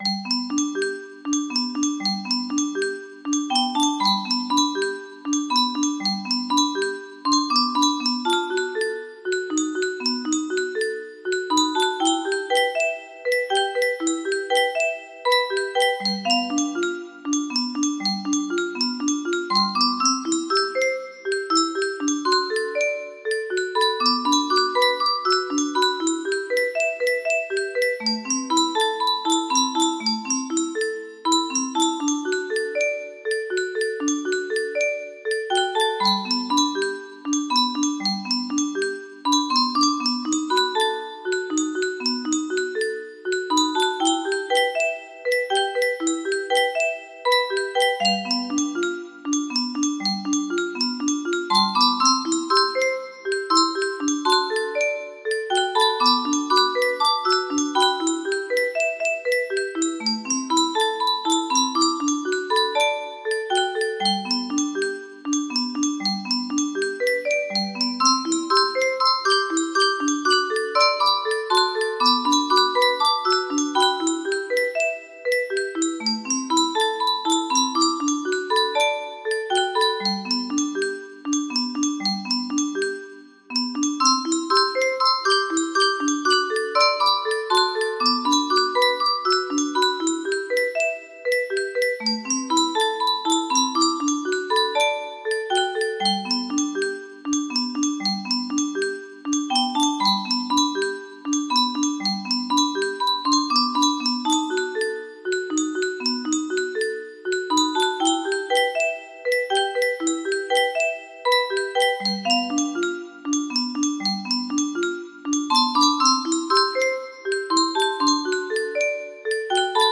Adaptación para Music Box.